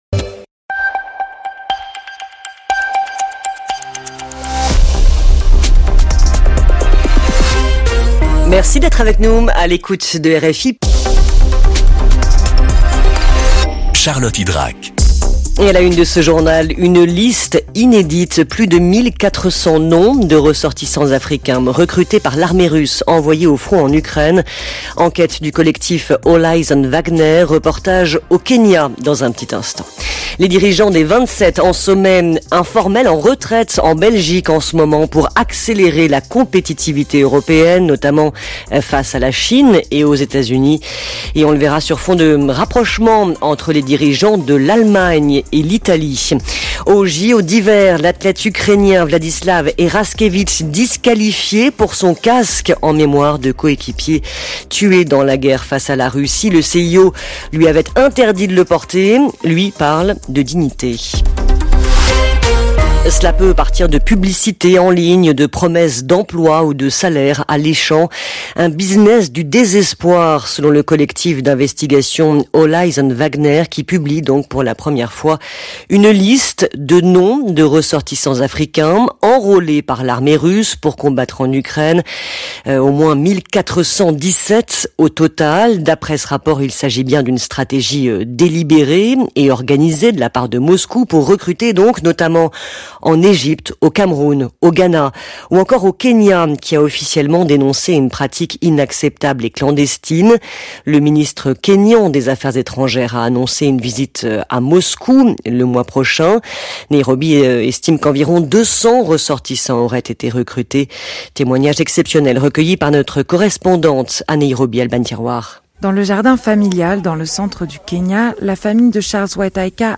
Flash infos 12/02/2026